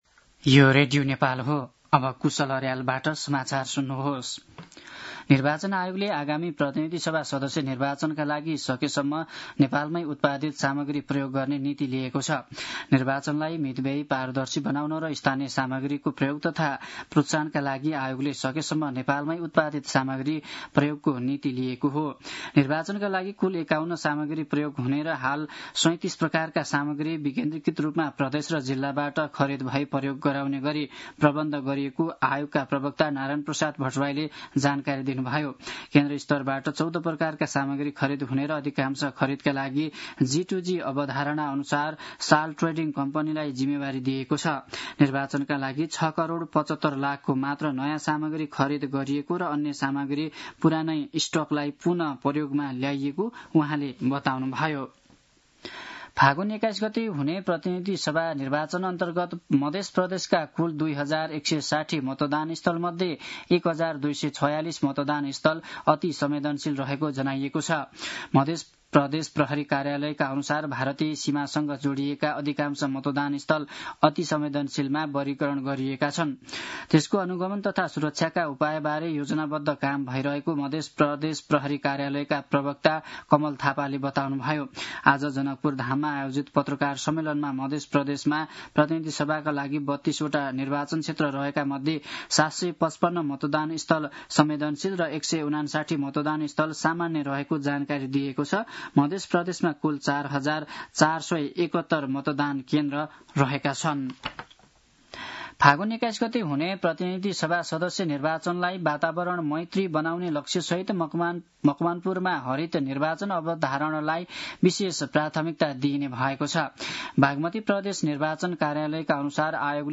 दिउँसो ४ बजेको नेपाली समाचार : १५ माघ , २०८२